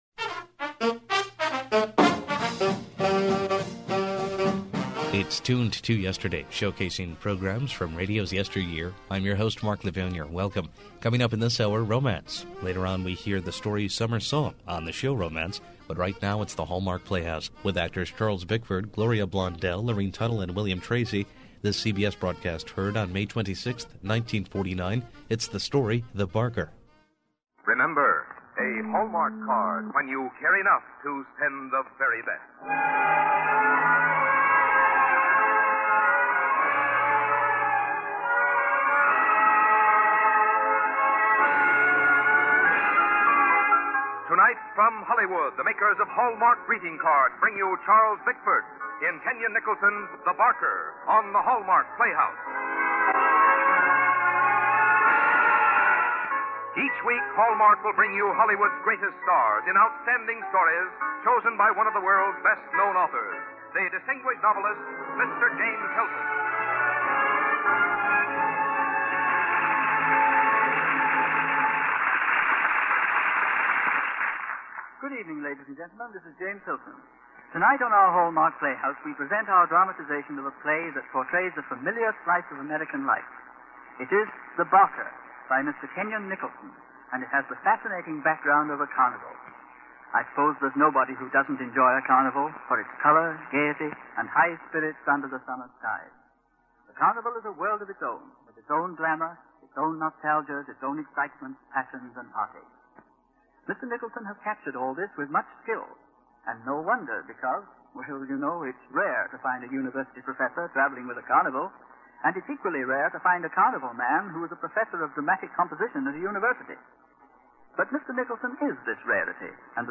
Decades ago, WRVO Public Media began broadcasting old-time radio with a small collection comprising 20 reel-to-reel tapes.
The highest quality broadcasts are restored and played as they were heard years and years ago.
Audio Drama